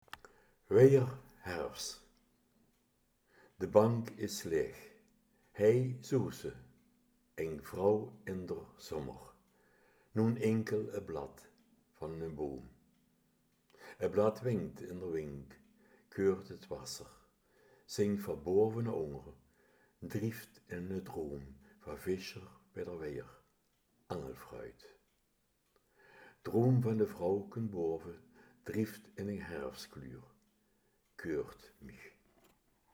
Weier herfs | Kerkraads Dialekt